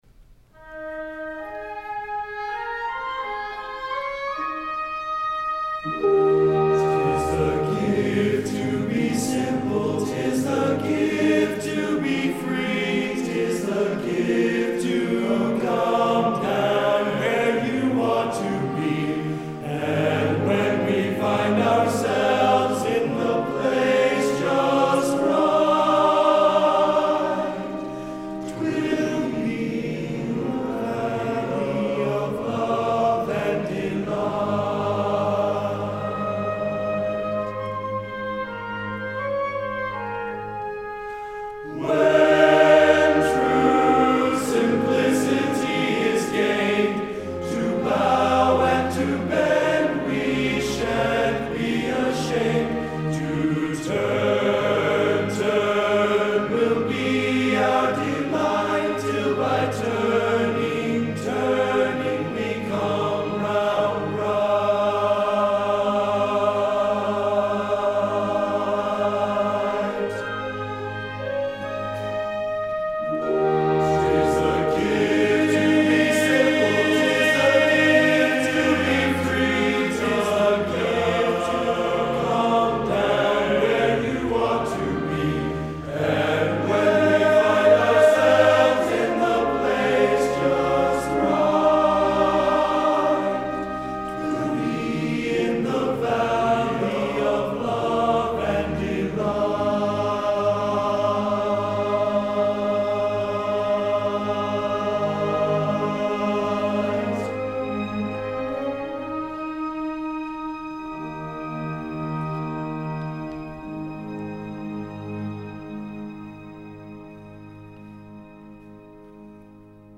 Genre: Traditional | Type: